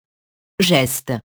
geste [ʒɛst]